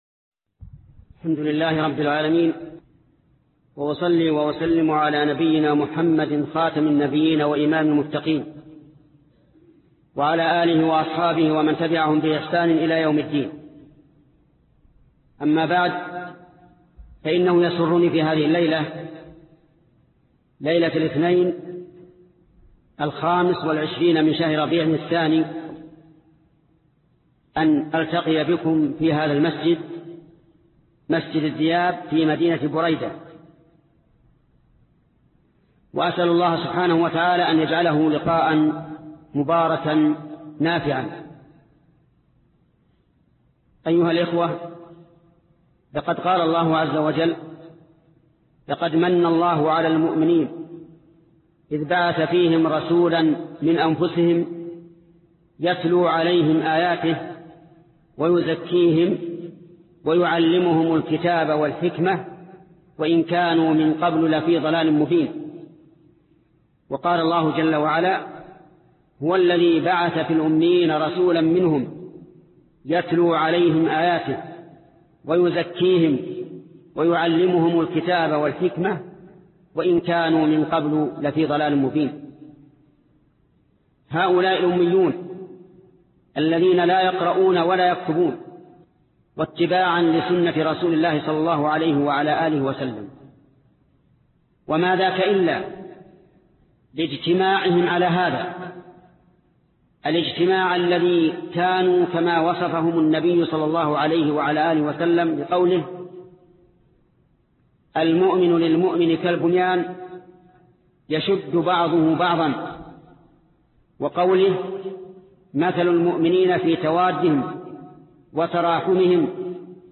شروط و اداب فى الدعوة - محاضرات الشيخ ابن عثيمين - فضيلة الشيخ محمد بن صالح العثيمين رحمه الله